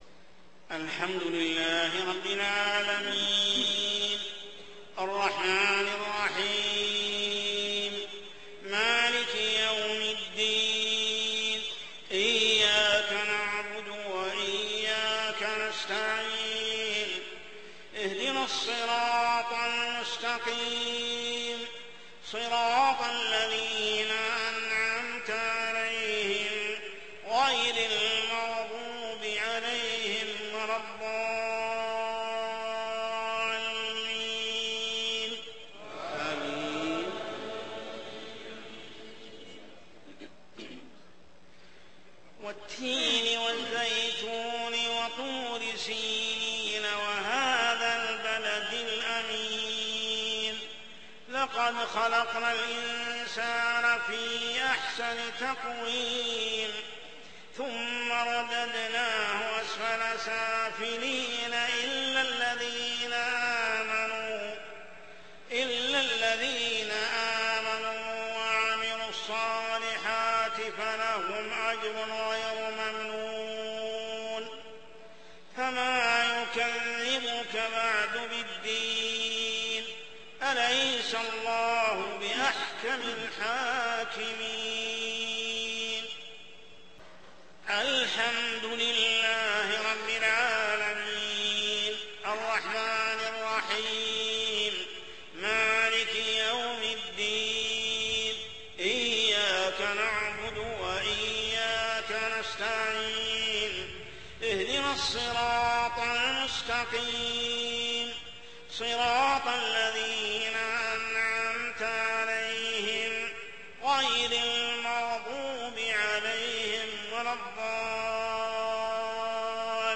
صلاة العشاء عام 1428هـ سورتي التين و القدر كاملة | Isha prayer surah at-Tin and al-qadr > 1428 🕋 > الفروض - تلاوات الحرمين